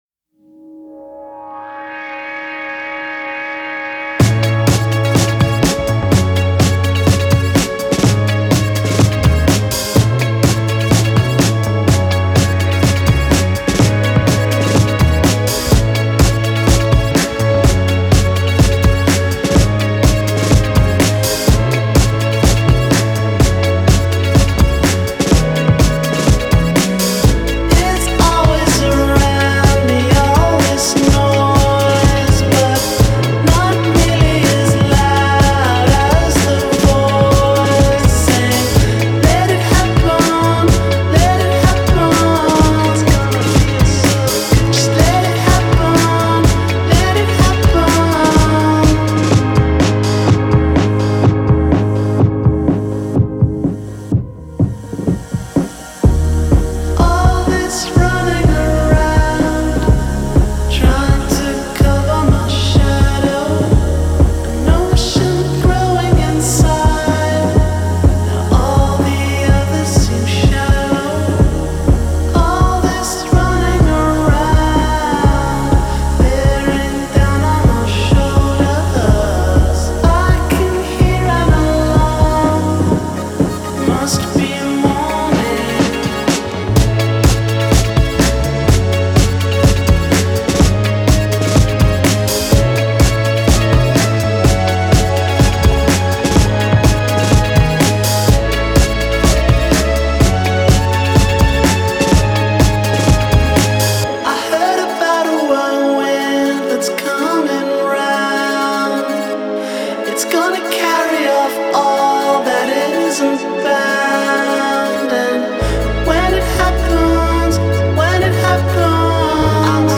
Genre: Indie Rock, Psychedelic